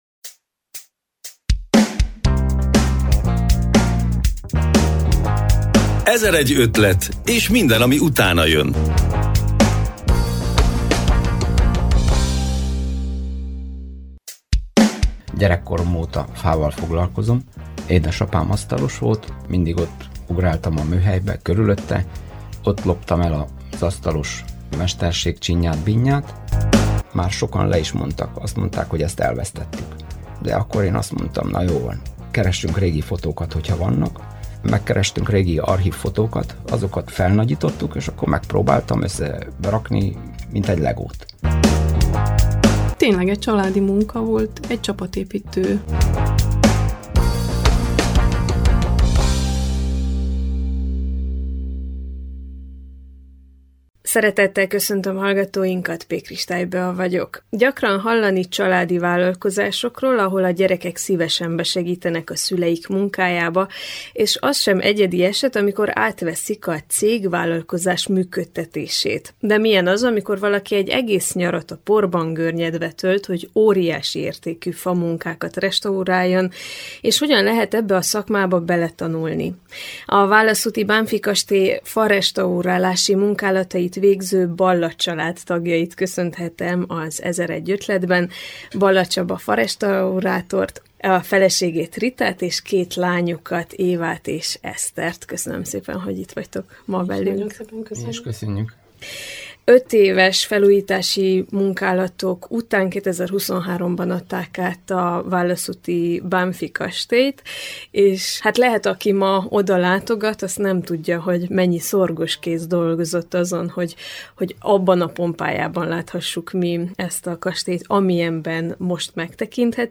Az 1001 ötletben a farestaurátori munkáról és egy család közös erőfeszítéseiről is beszélgettünk, a közös kapocs pedig a válaszúti Bánffy-kastély.